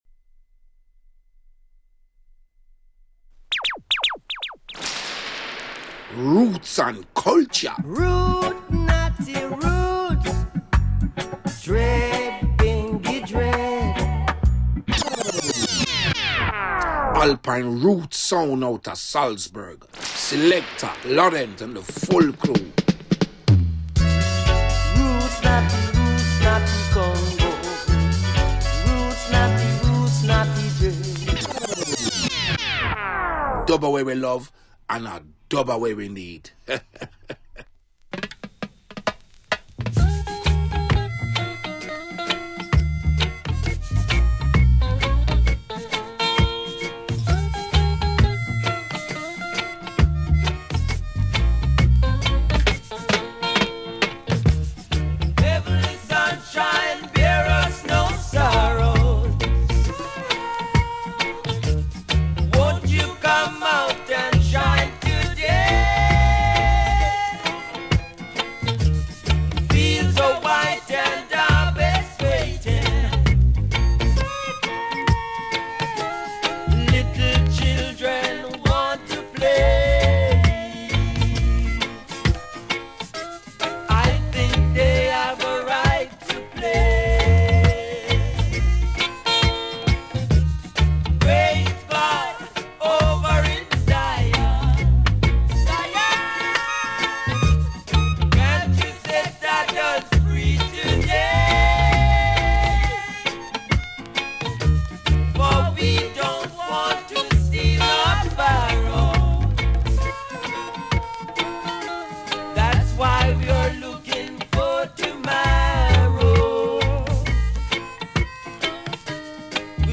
Live Radioshow